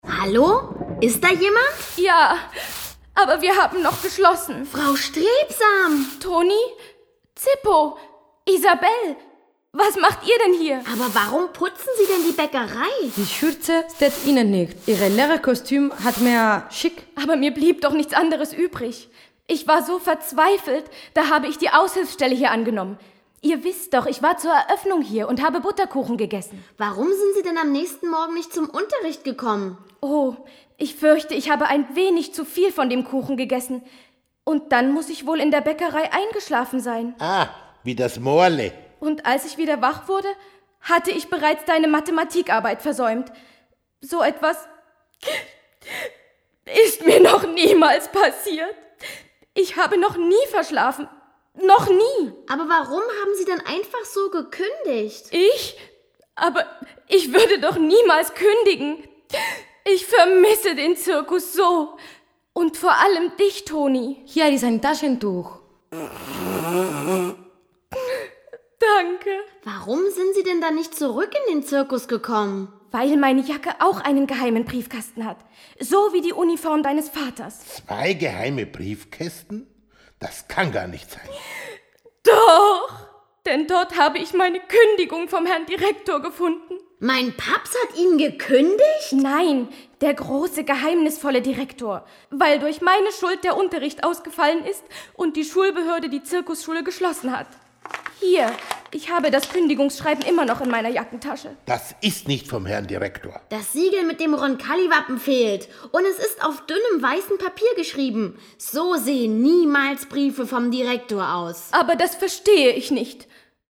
Hörspiel: Zirkus Roncalli – Die Zirkusschule fällt aus
Rolle: Frau Strebsam